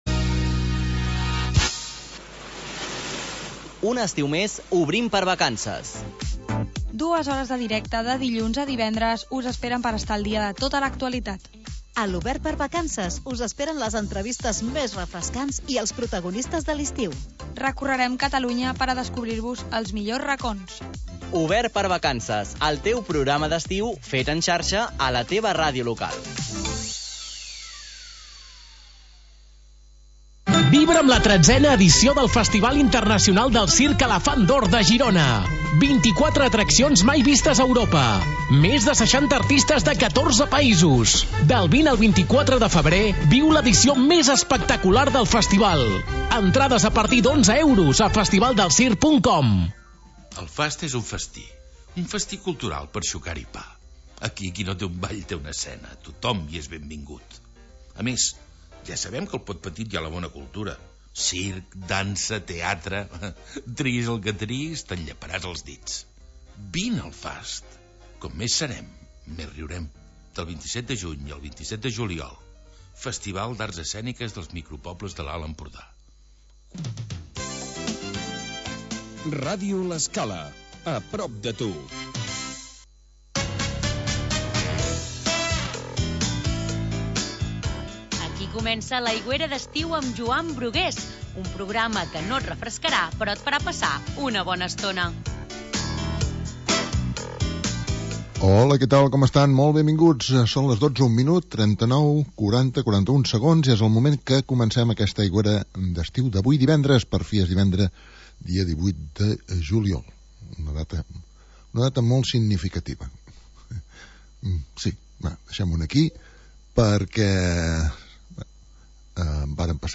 Magazín musical